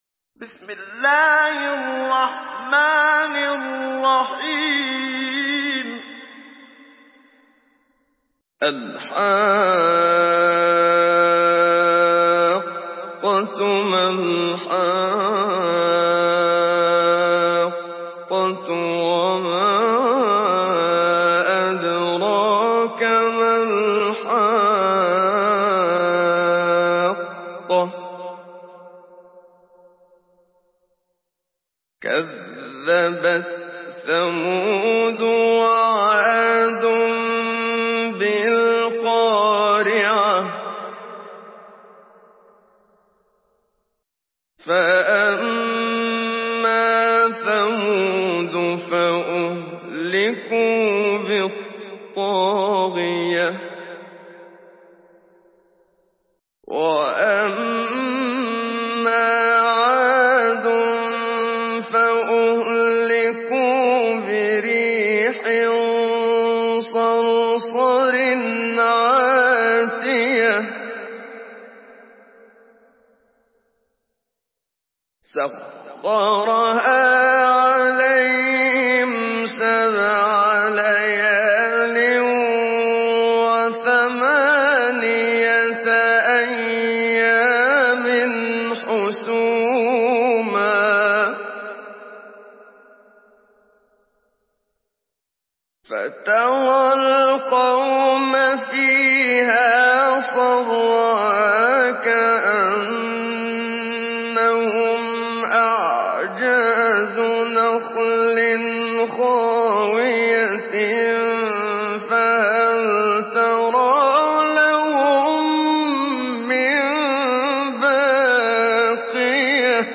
Surah আল-হাক্কাহ্ MP3 in the Voice of Muhammad Siddiq Minshawi Mujawwad in Hafs Narration
Surah আল-হাক্কাহ্ MP3 by Muhammad Siddiq Minshawi Mujawwad in Hafs An Asim narration. Listen and download the full recitation in MP3 format via direct and fast links in multiple qualities to your mobile phone.